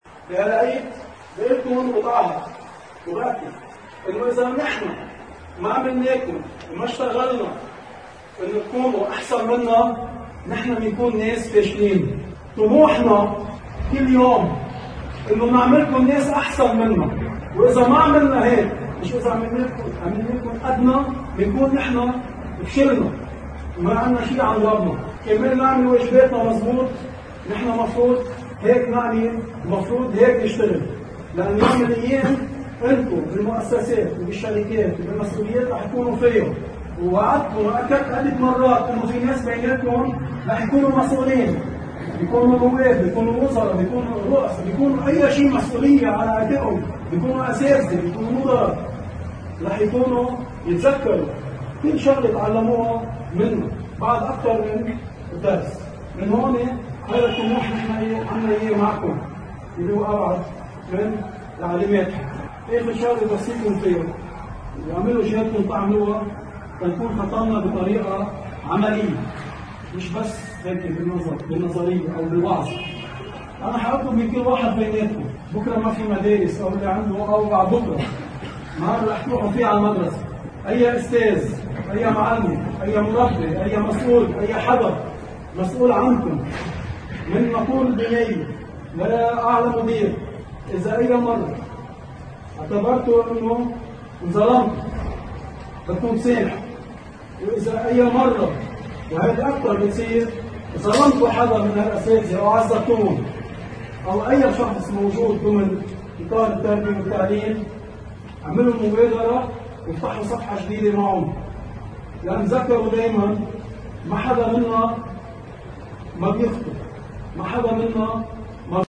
في عيد المعلّم